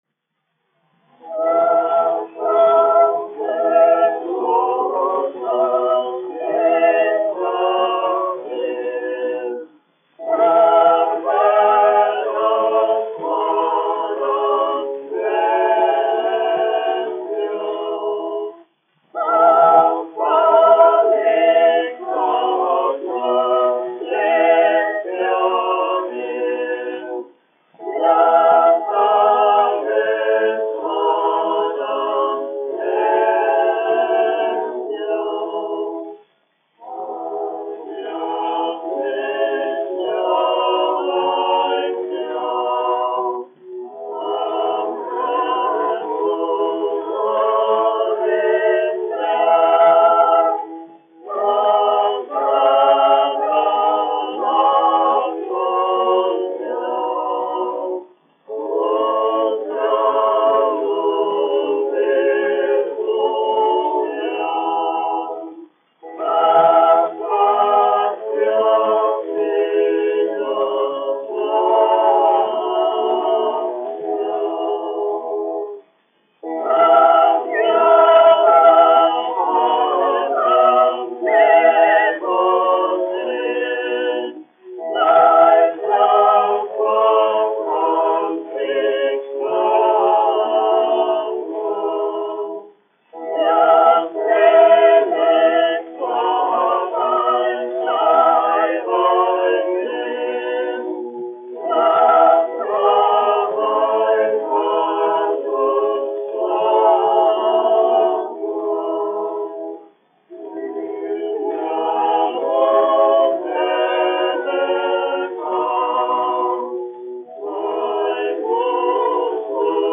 Rīgas Latviešu dziedāšanas biedrības jauktais koris, izpildītājs
1 skpl. : analogs, 78 apgr/min, mono ; 25 cm
Korāļi
Garīgās dziesmas
Skaņuplate